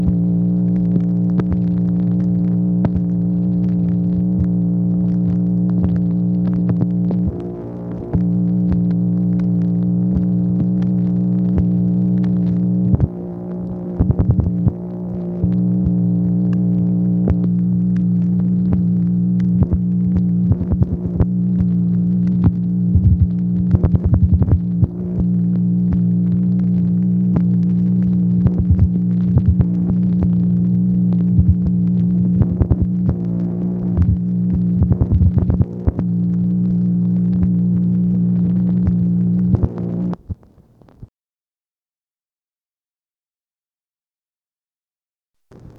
MACHINE NOISE, July 14, 1964
Secret White House Tapes | Lyndon B. Johnson Presidency